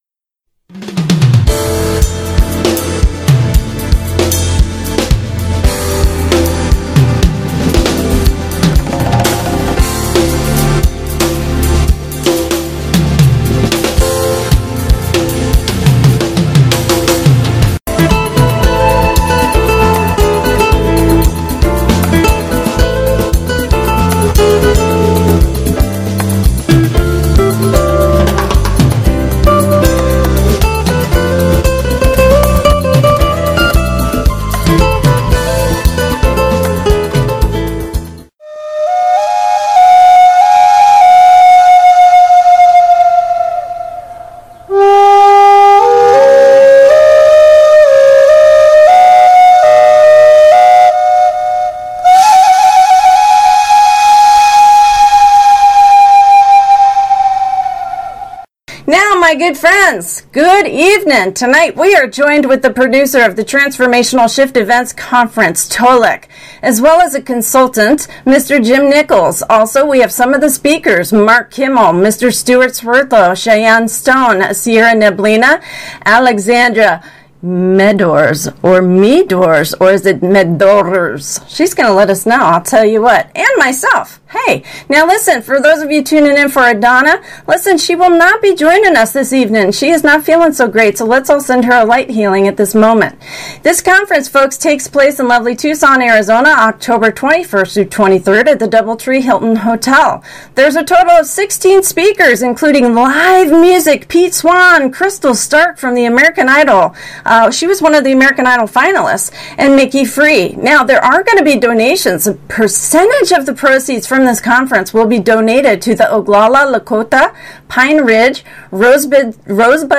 A brief rundown on the speakers interviewed here: